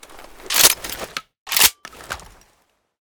unjam.ogg.bak